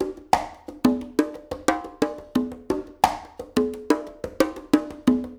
SMP CNGAS2-L.wav